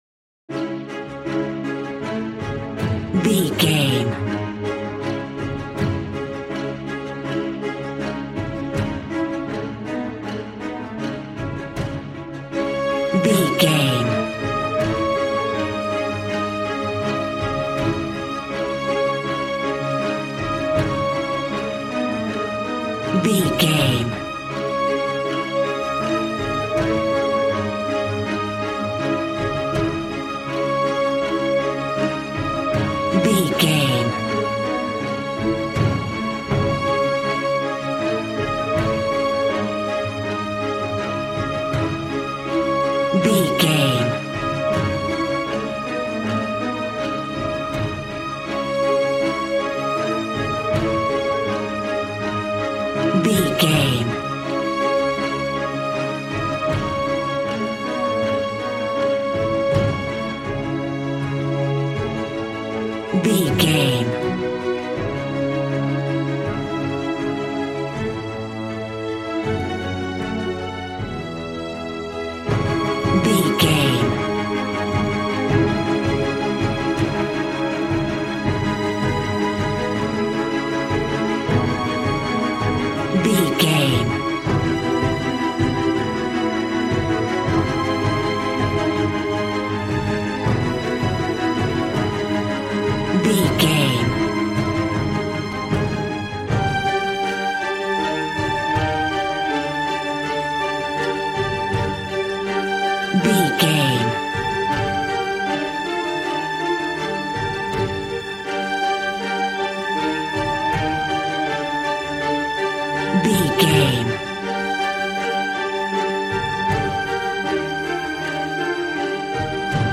Aeolian/Minor
D♭
dramatic
epic
strings
violin
brass